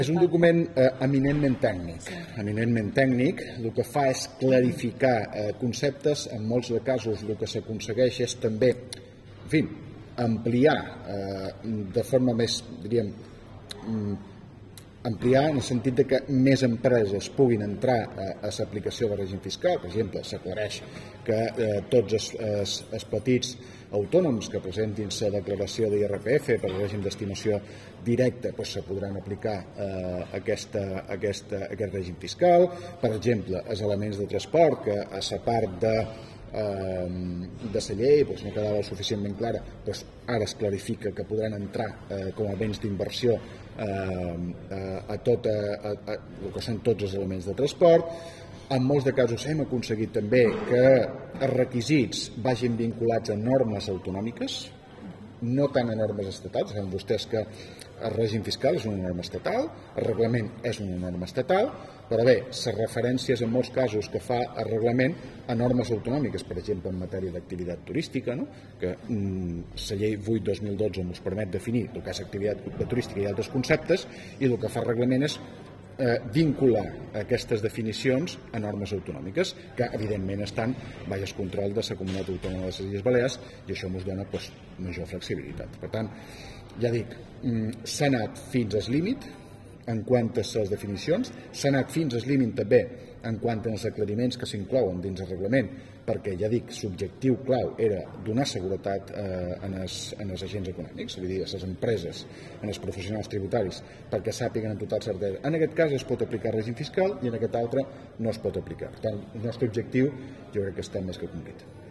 Reunió del vicepresident Costa amb els agents econòmics i empresarials de les Illes Balears 06/03/2024
Declaracions vicepresident Costa